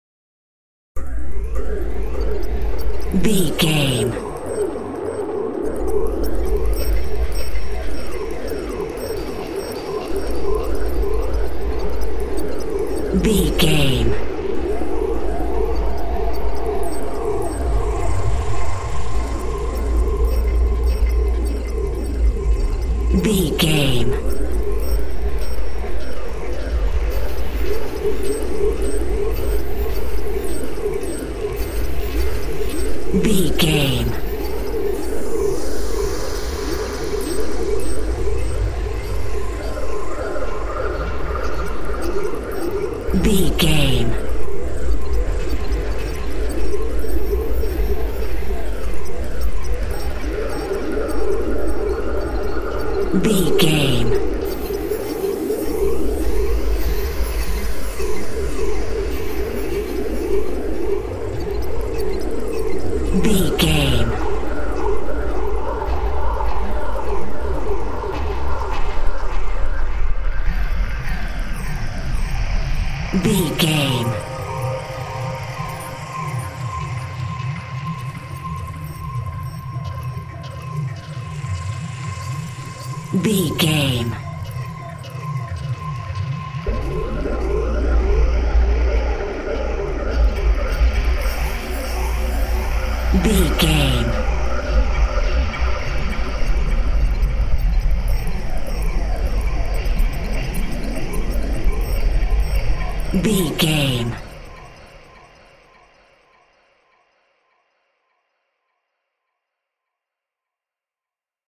In-crescendo
Thriller
Aeolian/Minor
tension
ominous
eerie
Horror synth
Horror Ambience
electronics
synthesizer